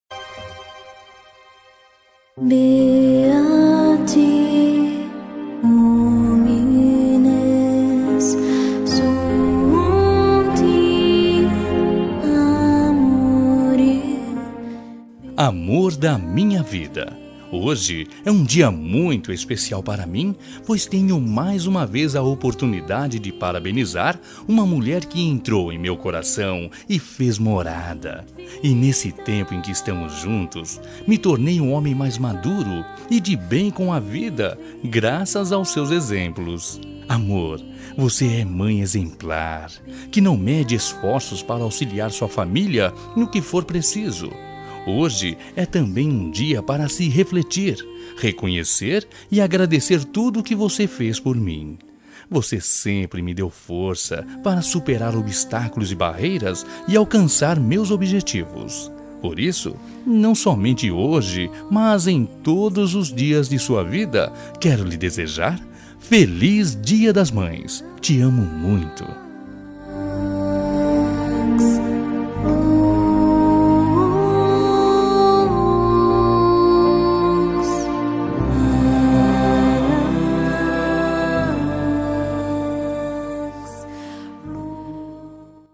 Voz Masculina